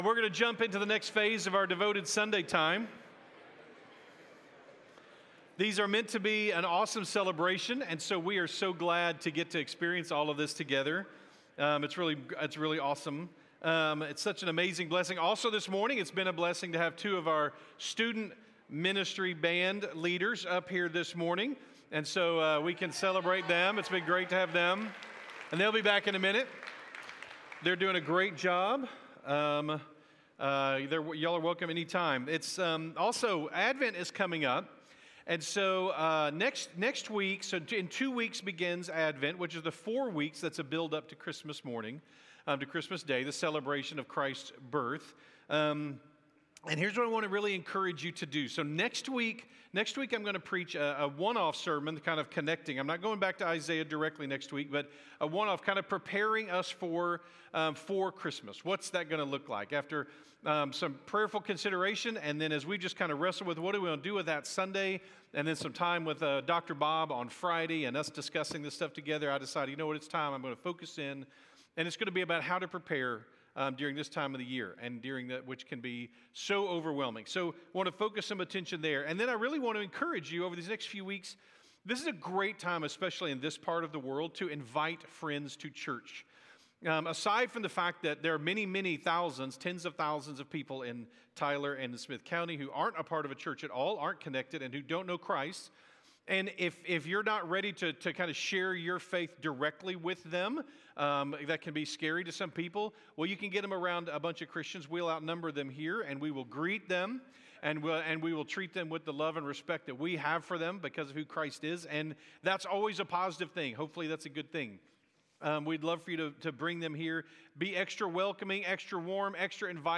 by South Spring Media | Nov 16, 2025 | 2025 Sermons, Blog, Devoted | 0 comments